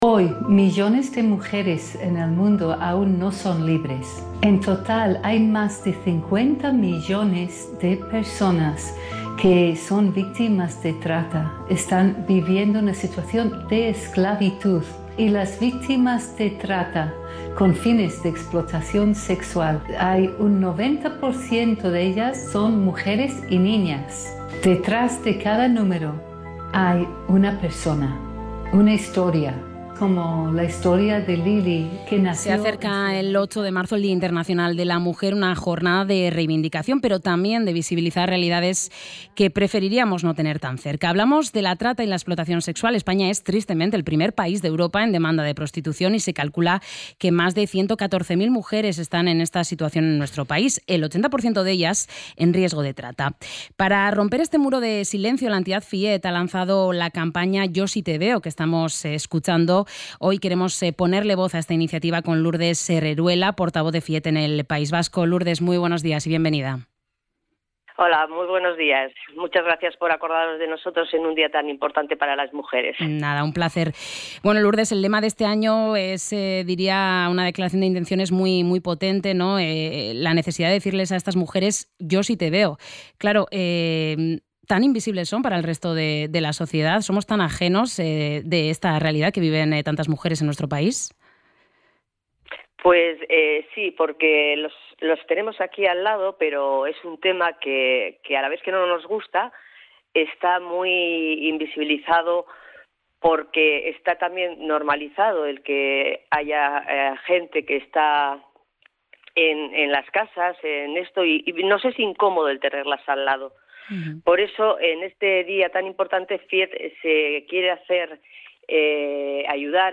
ENTREVISTA-CAMPANA-TRATA.mp3